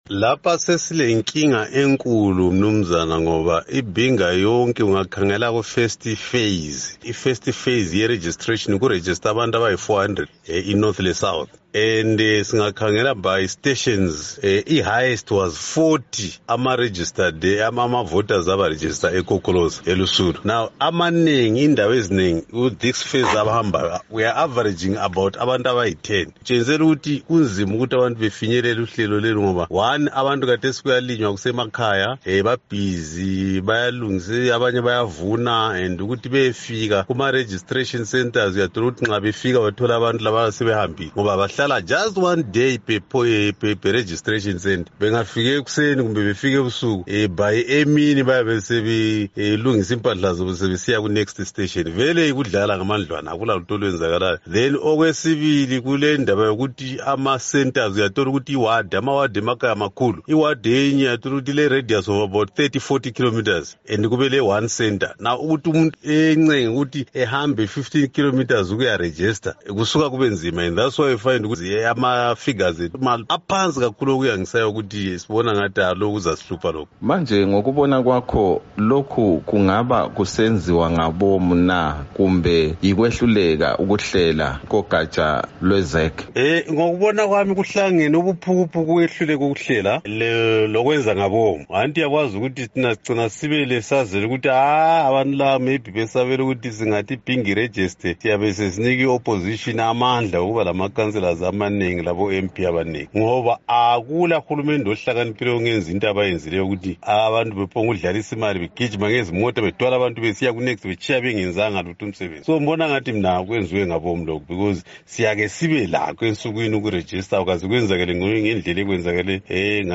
Ingxoxo loMnu. Joel Gabhuza